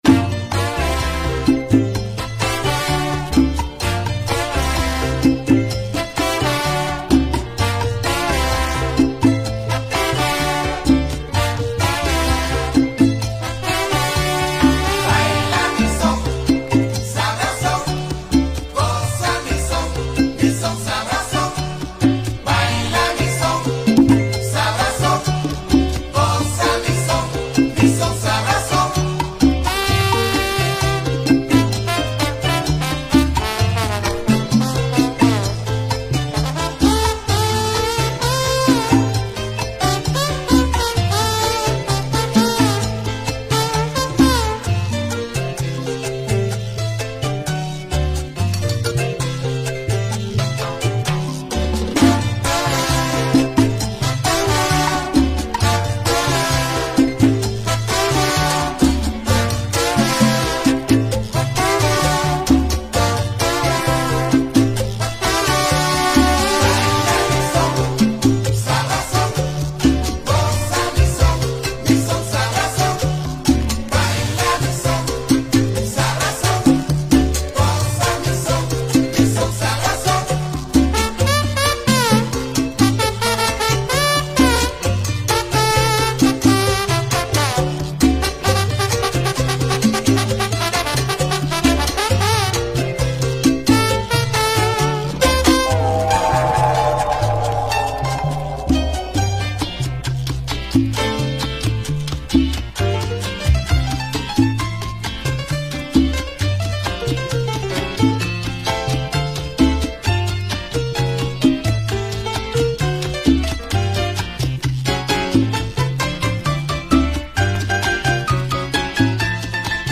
Early 80's Venezuelan salsa album
great latin jazz tune